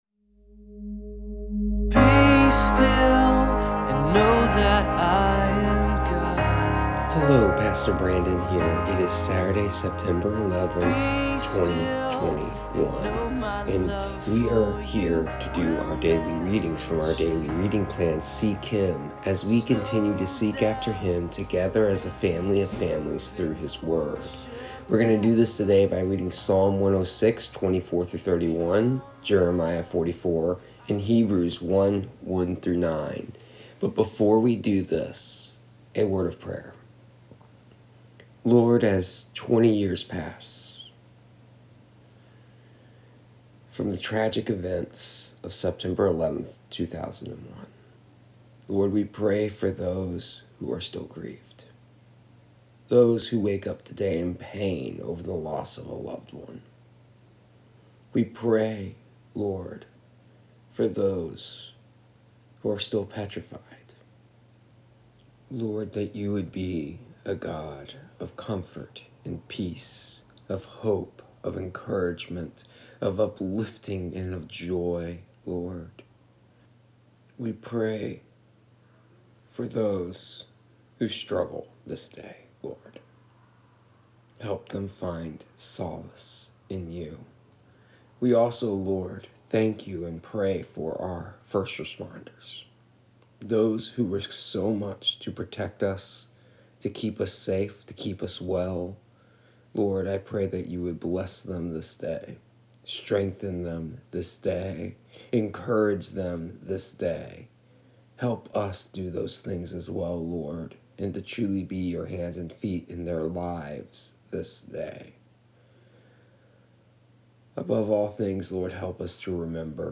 But before we do that a prayer for this day as we remember the pains of the past and seek God continually for the healing of our hearts, nations, and lands.
Here is the audio version of our daily readings from our daily reading plan Seek Him for September 11th, 2021.